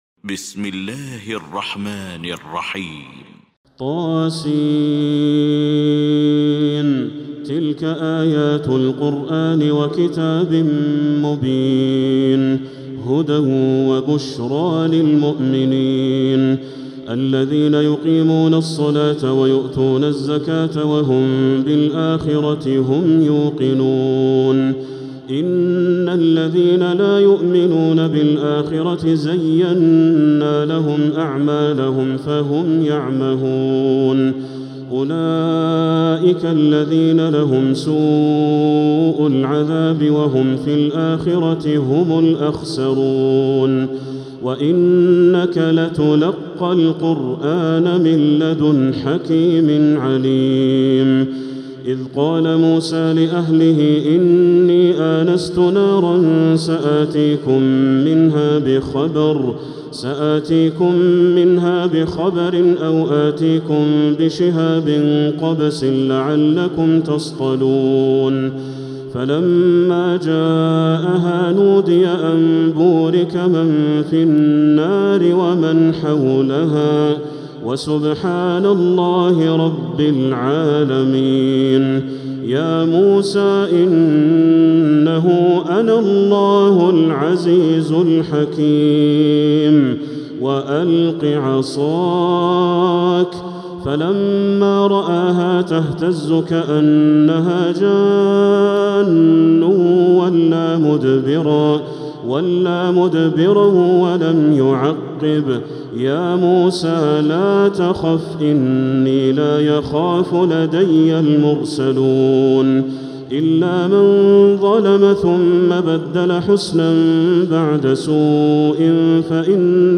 المكان: المسجد الحرام الشيخ: بدر التركي بدر التركي فضيلة الشيخ ياسر الدوسري النمل